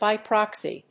アクセントby próxy